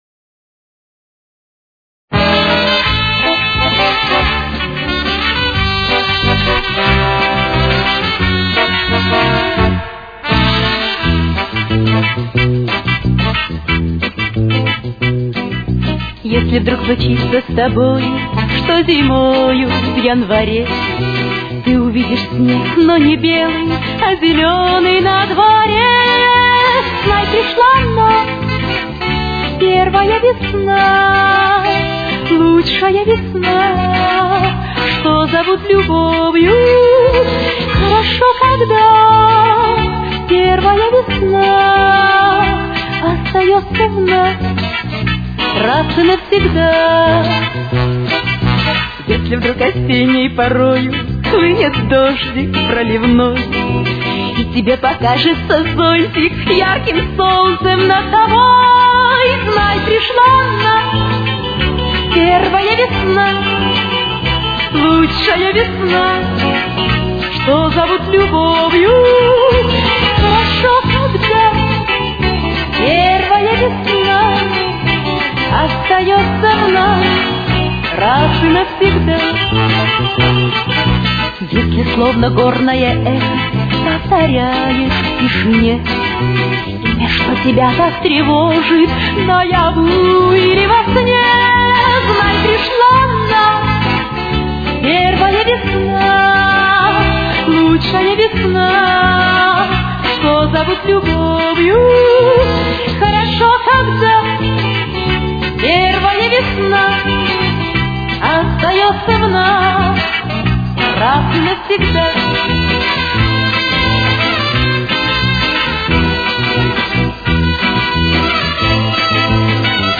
Темп: 183.